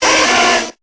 Cri de Salamèche dans Pokémon Épée et Bouclier.